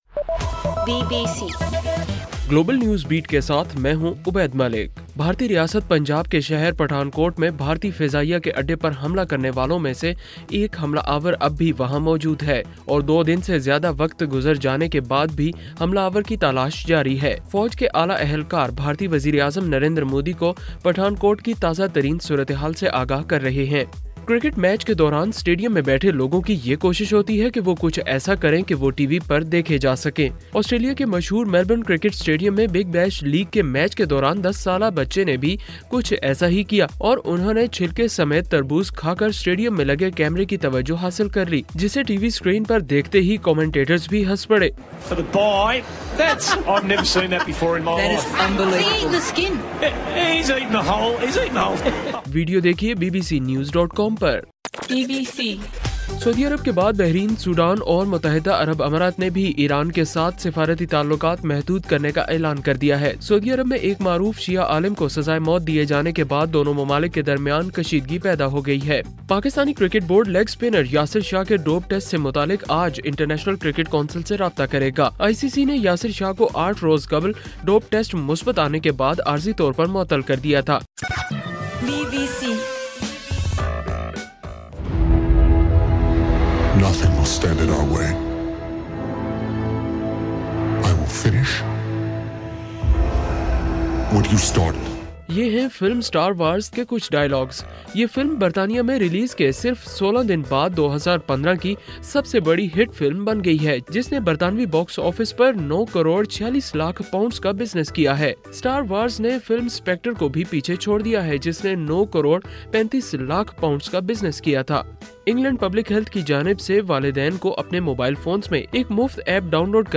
جنوری 04: رات 8 بجے کا گلوبل نیوز بیٹ بُلیٹن